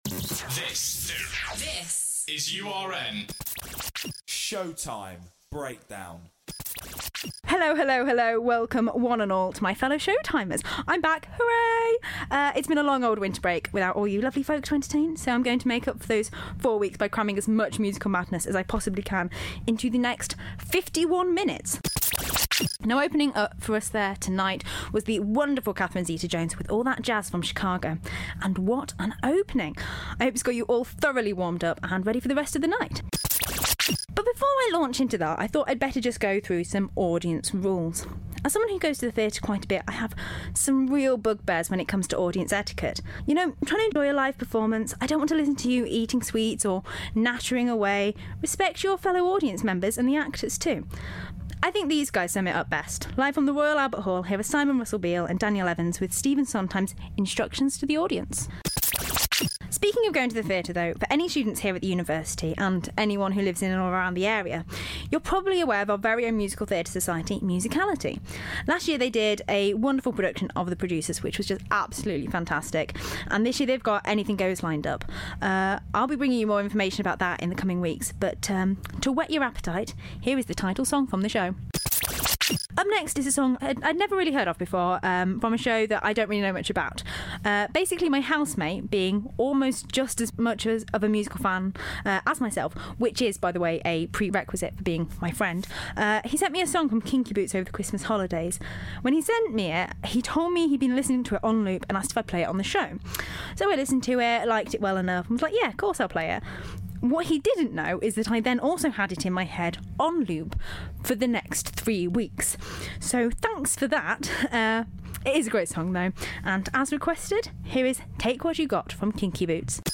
Join me in the first show of the year (due to major technical difficulties last week), in which I cover some of the many shows touring the UK tour this year as well as playing some of my favourite musical hits and your requests.